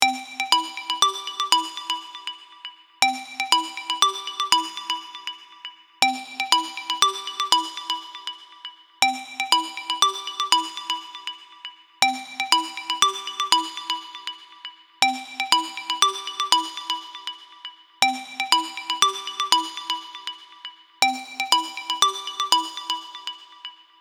phone-ringtone-clean-273554.mp3